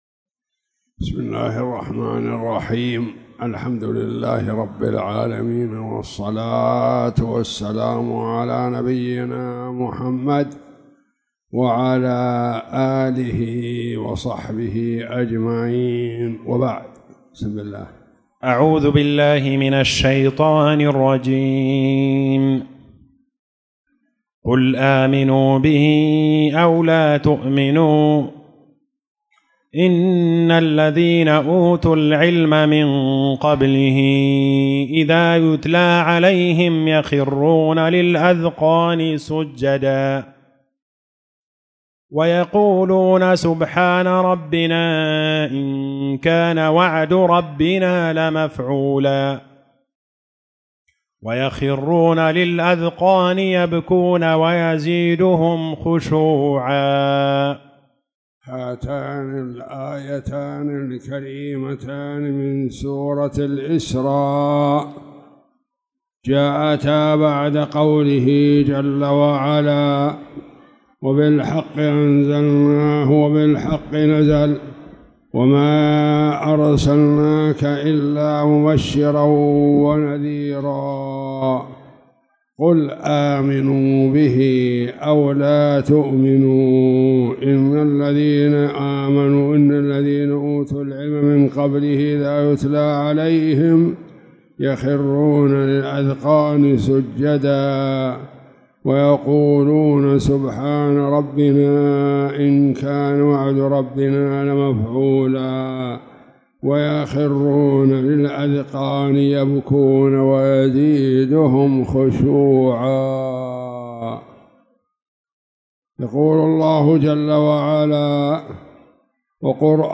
تاريخ النشر ٦ ربيع الثاني ١٤٣٨ هـ المكان: المسجد الحرام الشيخ